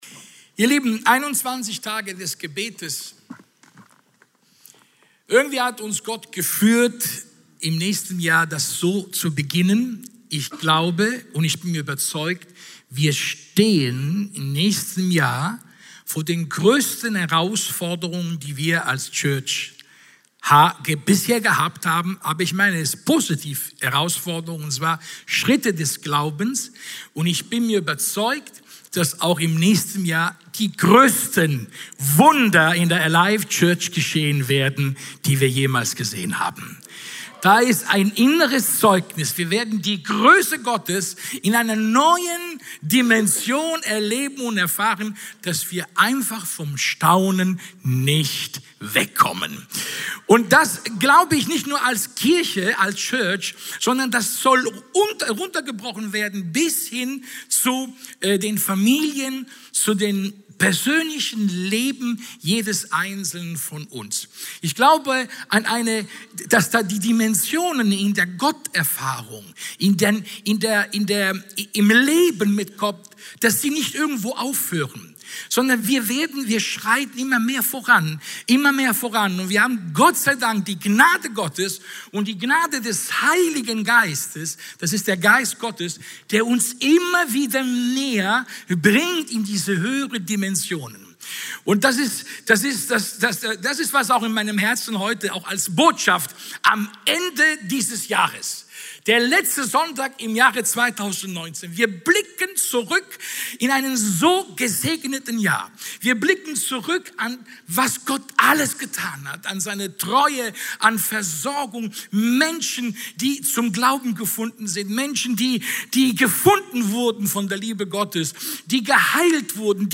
Dienstart: Sonntag